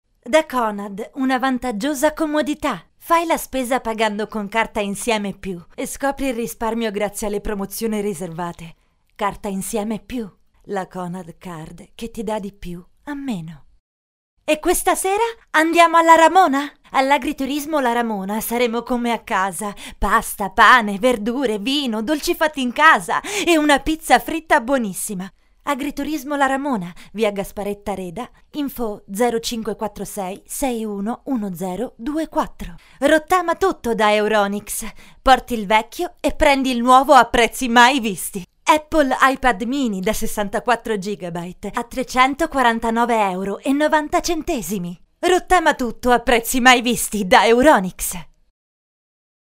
Italian actress. voice actress and singer.
Kein Dialekt
Sprechprobe: Industrie (Muttersprache):